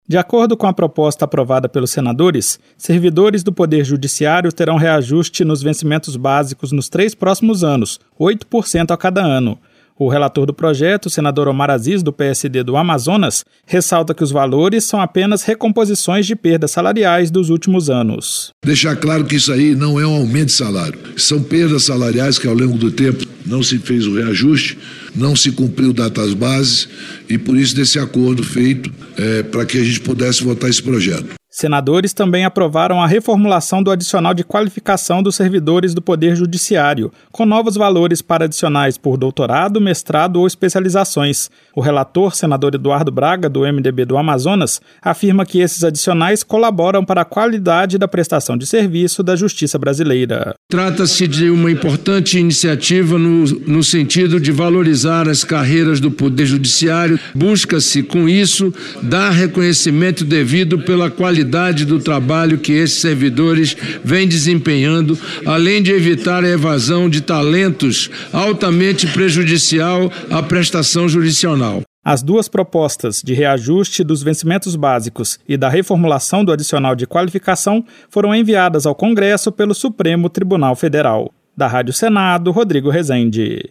Senador Eduardo Braga
Senador Omar Aziz